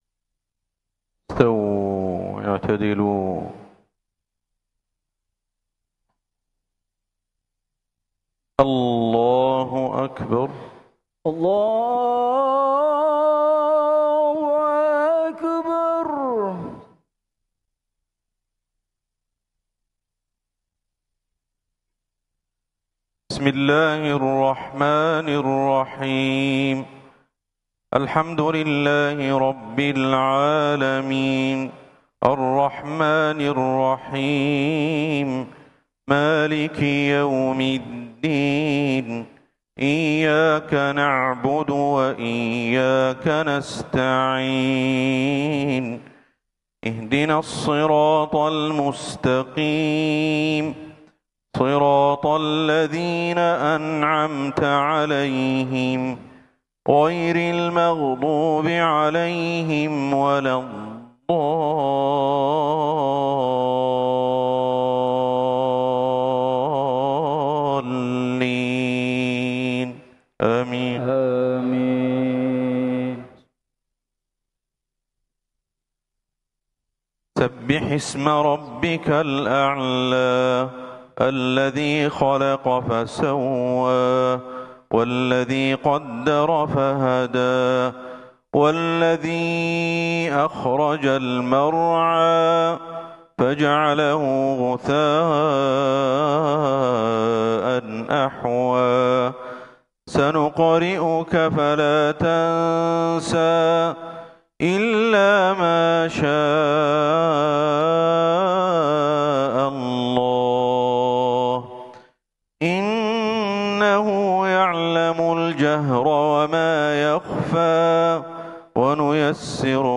صلاة الجمعة ٨ ربيع الآخر ١٤٤٦هـ بمسجد الإستقلال الكبير بإندونيسيا 🇮🇩 > زيارة الشيخ أحمد الحذيفي لجمهورية إندونيسيا > المزيد - تلاوات الشيخ أحمد الحذيفي